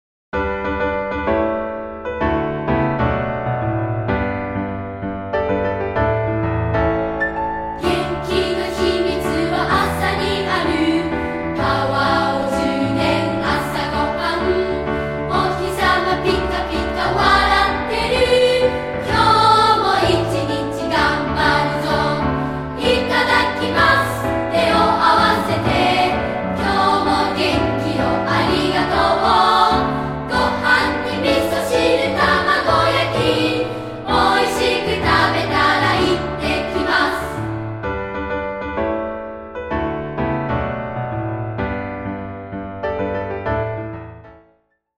斉唱・2部合唱／伴奏：ピアノ